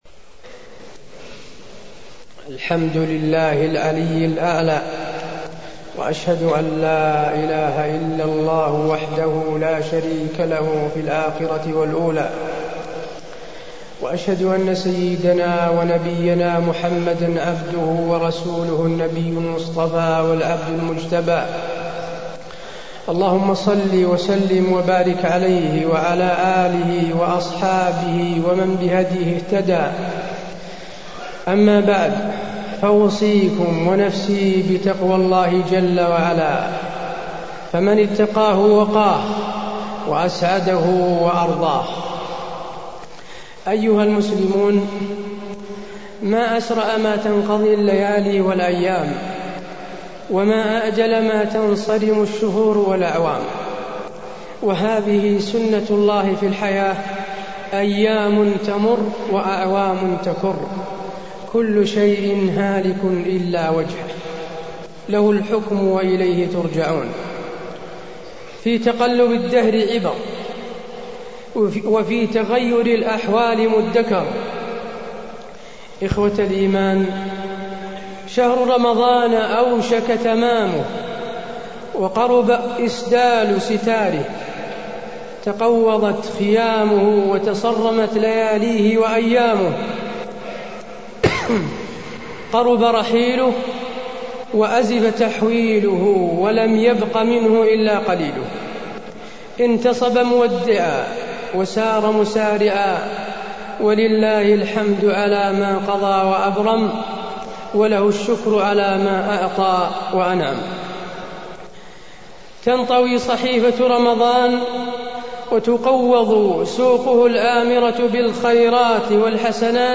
تاريخ النشر ٢٤ رمضان ١٤٢٣ هـ المكان: المسجد النبوي الشيخ: فضيلة الشيخ د. حسين بن عبدالعزيز آل الشيخ فضيلة الشيخ د. حسين بن عبدالعزيز آل الشيخ توديع شهر رمضان